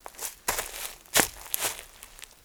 MISC Leaves, Foot Scrape 03.wav